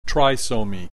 click this icon to hear the preceding term pronounced